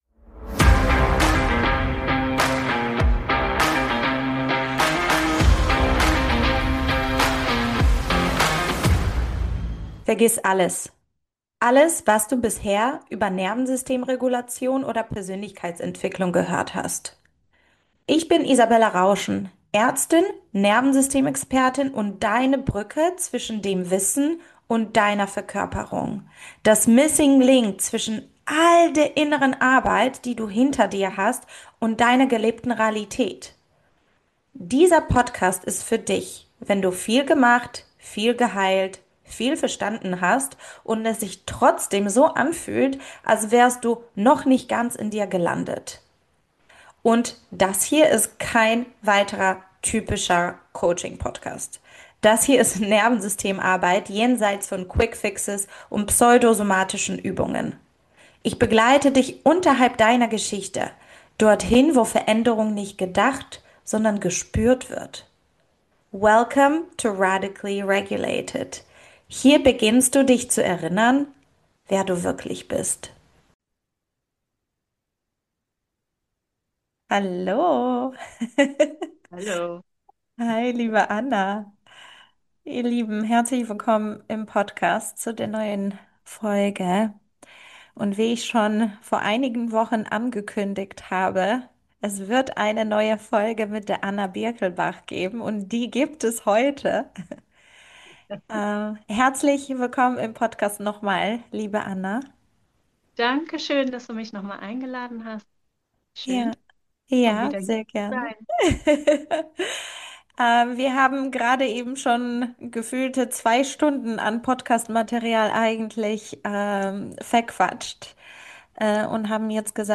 Diese Folge ist kein klassischer Interview-Podcast, sondern ein ehrliches, lebendiges und teilweise auch sehr spontanes Gespräch zwischen zwei Menschen, die diese Arbeit nicht nur weitergeben, sondern selbst immer wieder neu darin eintauchen.